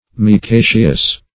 Micaceous \Mi*ca"ceous\, a.
micaceous.mp3